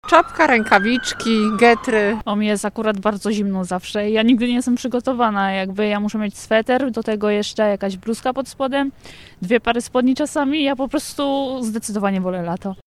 Warunki pogodowe sprawdzaliśmy na zewnątrz z mikrofonem.
zima-sonda-2z2.mp3